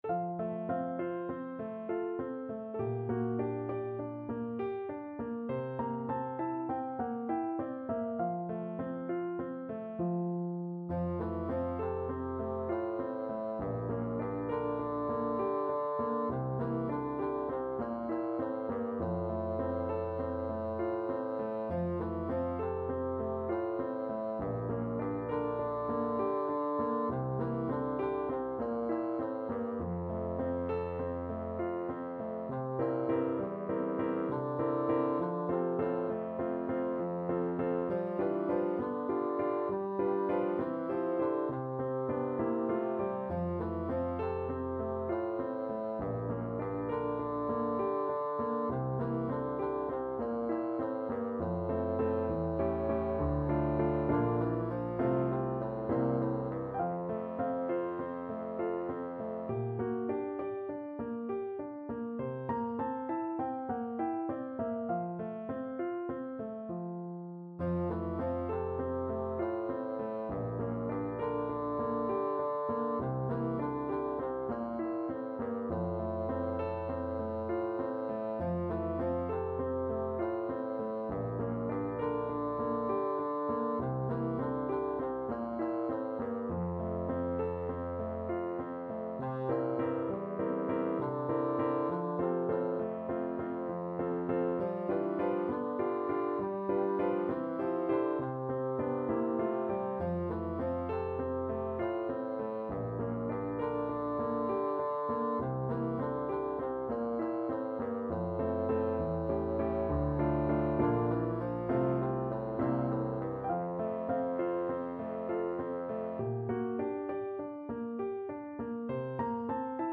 Bassoon
Beautiful Dreamer is a parlor song by Stephen Foster.
Moderato
F major (Sounding Pitch) (View more F major Music for Bassoon )
9/8 (View more 9/8 Music)